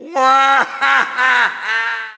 Wario goes WAAHAHAAHAAAAA as he falls off in Mario Kart Wii.